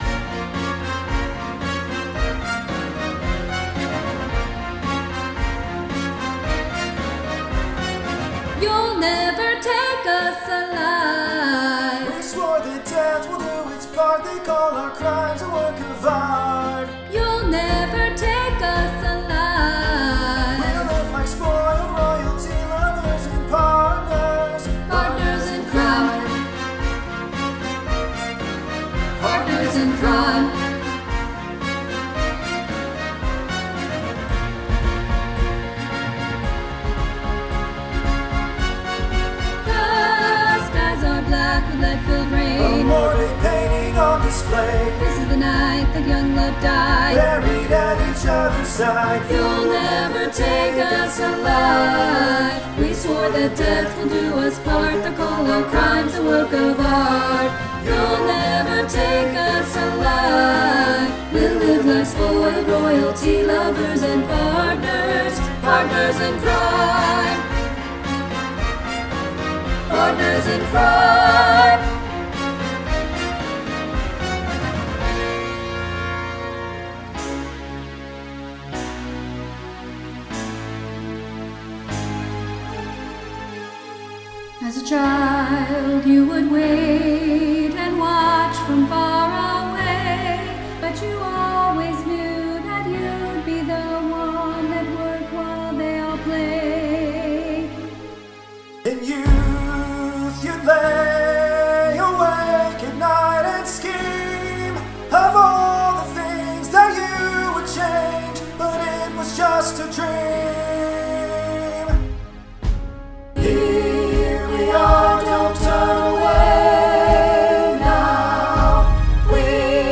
SATB